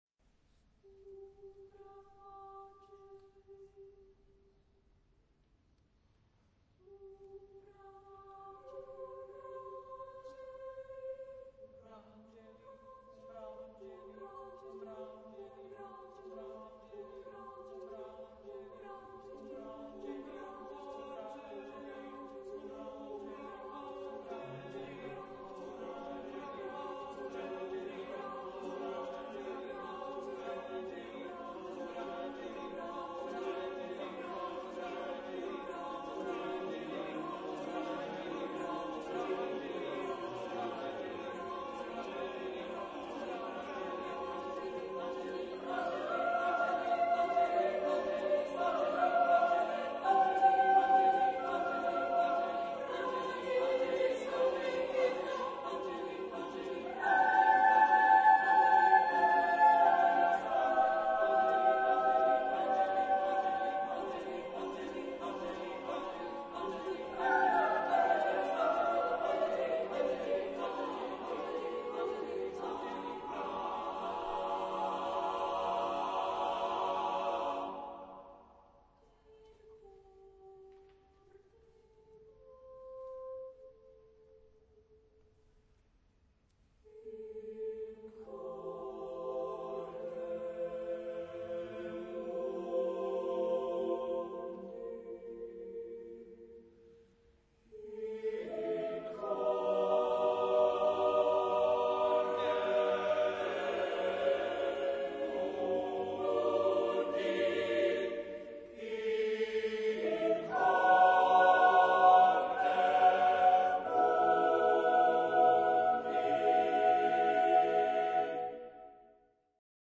SSAATTBB (8 voices mixed) ; Full score.
Choral song.